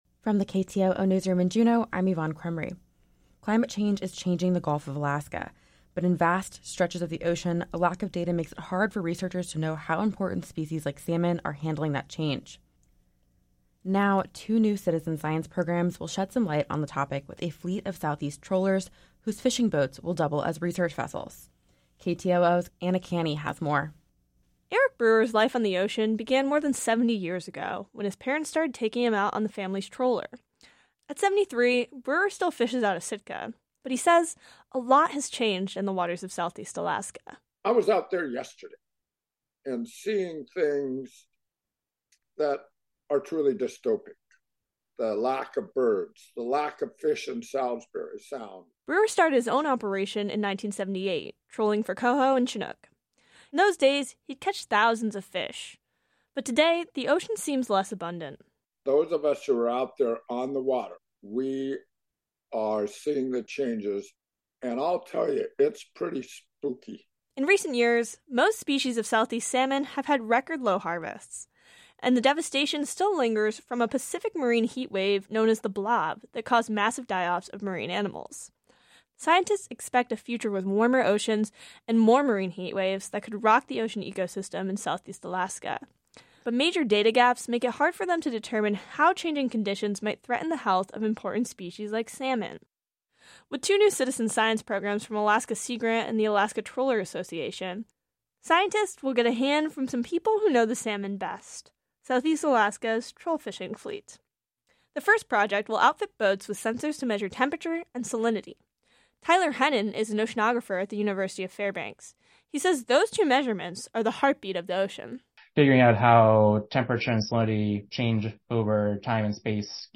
Newscast - Thursday, June 29, 2023